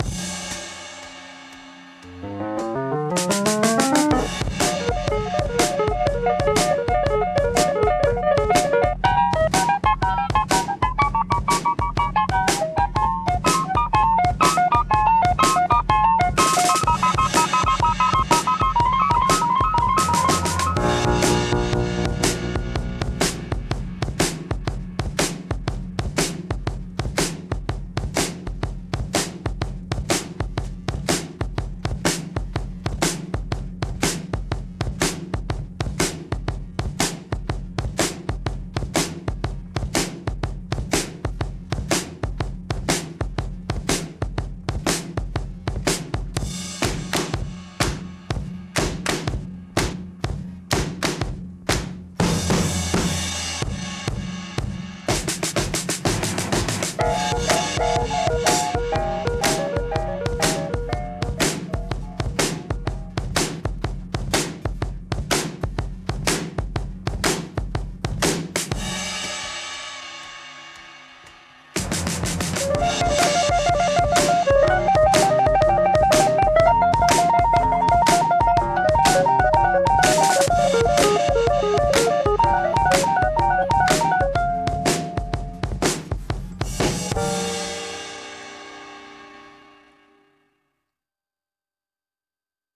electric piano